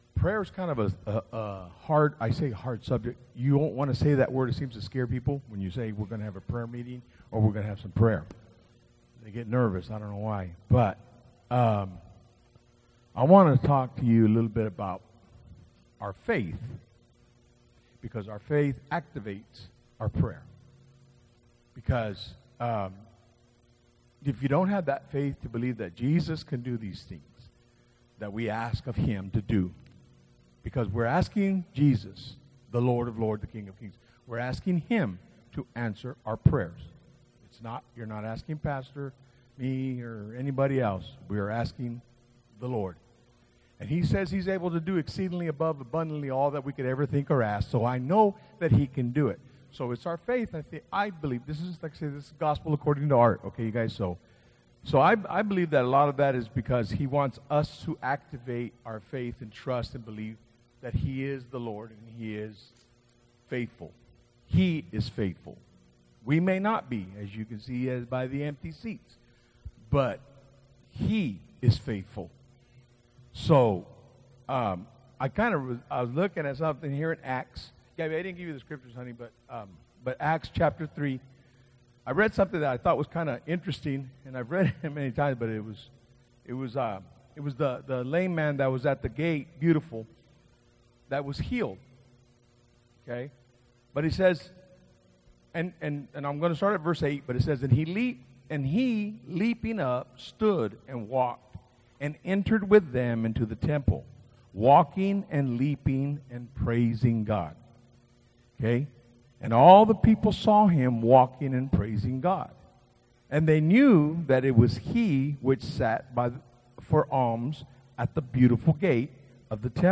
Genre: Speech.
series: Bible Studies